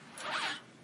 交通声音 " RUBBER WHEEL
描述：今天记录了我的自行车。它很快。
Tag: 自行车 自行车 橡胶